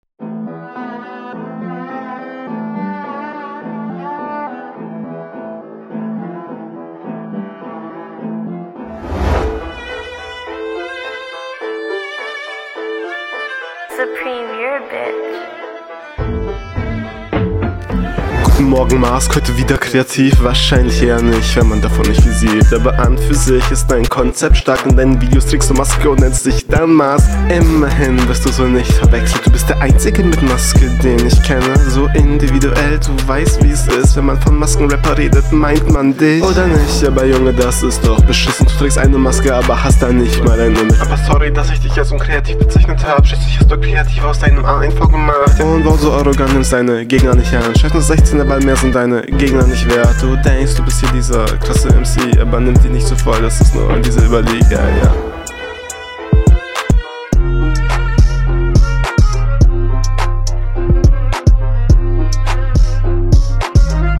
kreative flowideen, die mir zum teil nicht sauber genug umgesetzt worden sind. text hat gute …